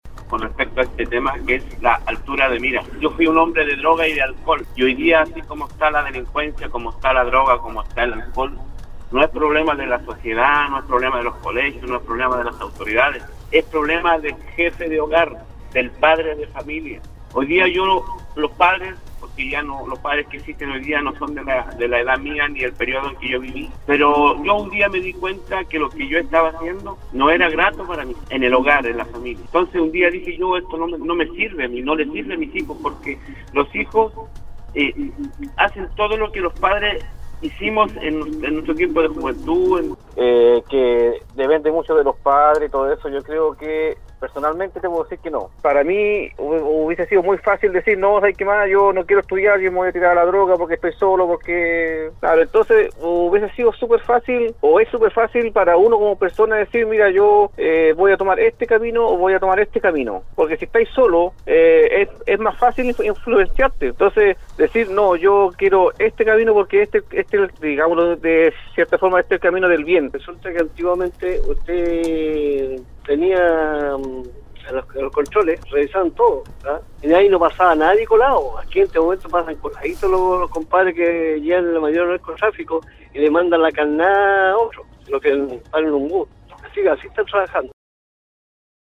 La mañana de este lunes, auditores de Nostálgica participaron en el foro del programa Al Día, donde se refirieron a cómo enfrentar los problemas de consumo y tráfico de drogas en las instituciones educativas del país.